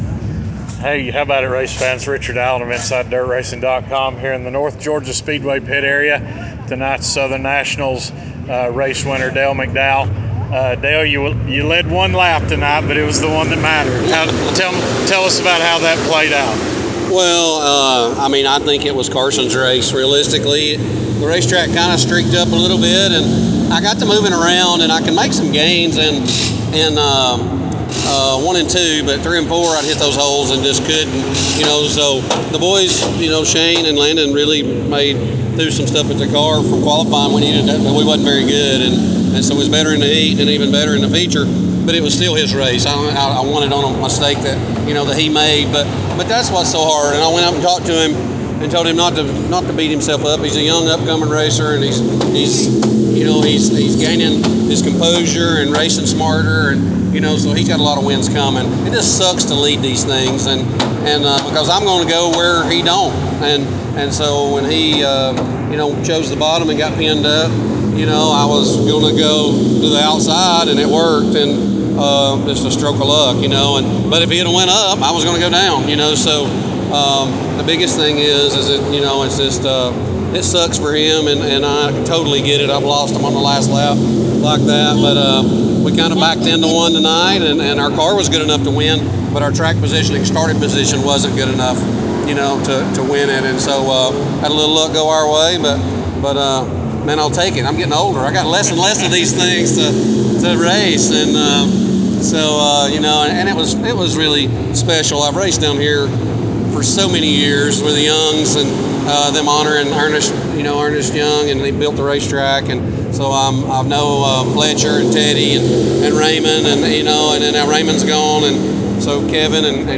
post-race interview